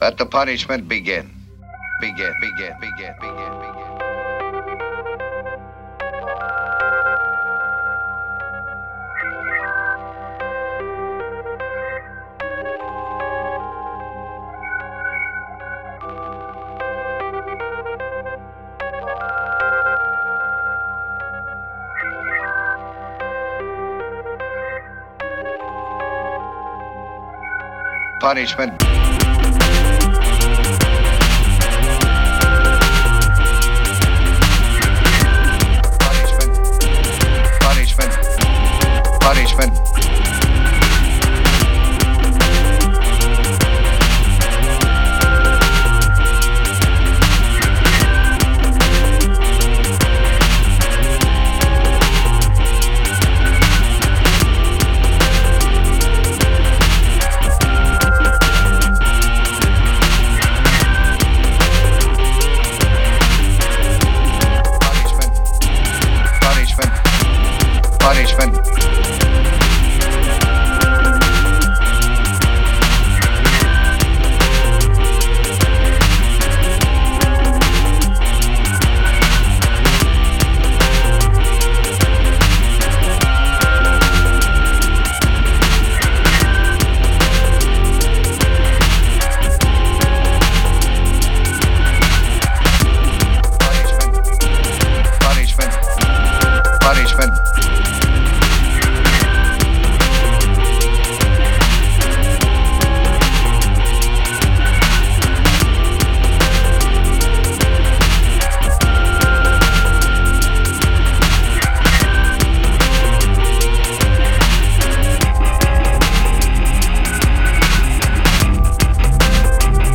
I did a little playing around with Garage Band and assembled a light little ditty for y’all. It’s from the Goth/Techno group AngstHämmer.